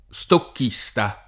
vai all'elenco alfabetico delle voci ingrandisci il carattere 100% rimpicciolisci il carattere stampa invia tramite posta elettronica codividi su Facebook stocchista [ S tokk &S ta ] (meno bene stockista [ id. ]) s. m. e f. (econ.); pl. m. -sti